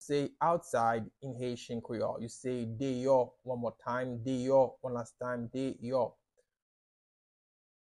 Listen to and watch “Deyò” pronunciation in Haitian Creole by a native Haitian  in the video below:
How-to-say-Outside-in-Haitian-Creole-Deyo-pronunciation-by-a-Haitian-teacher.mp3